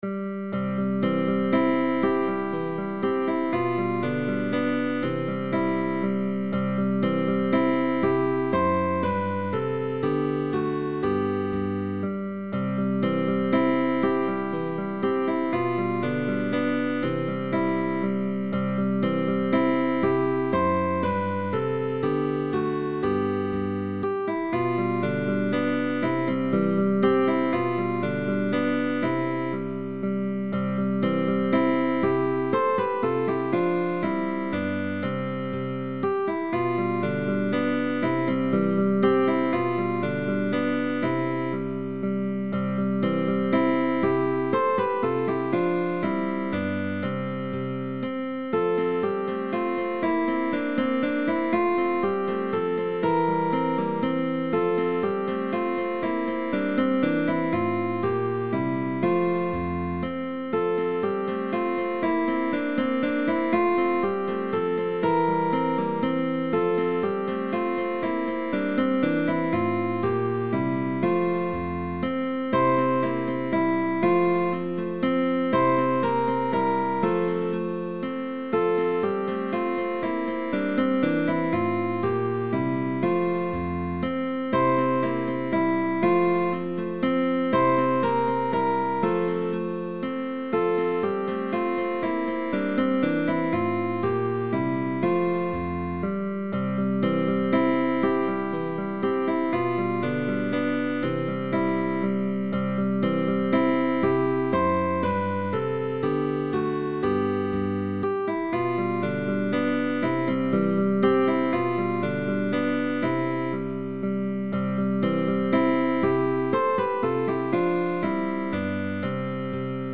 Classicism